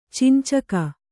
♪ cincaka